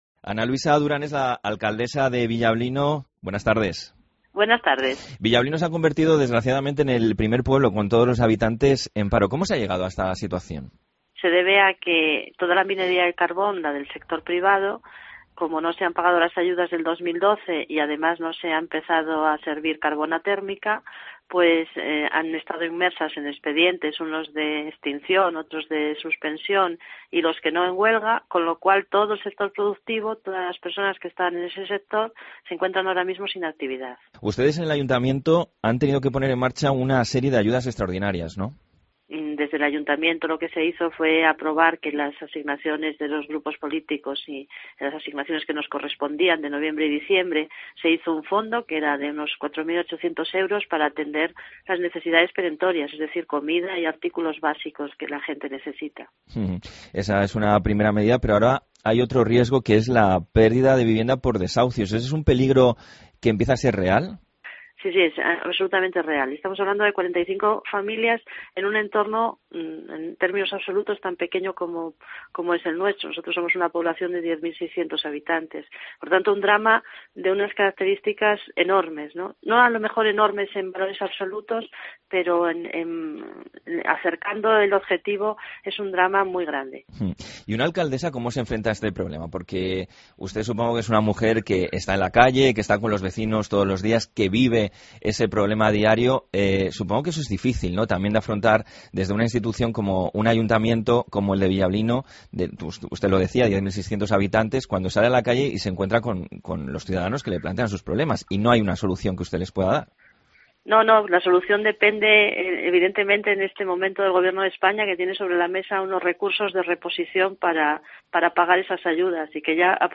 Escucha a la alcaldesa de Villablino, el único pueblo con todos sus habitantes en paro